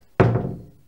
Spatial Door To Closing Botão de Som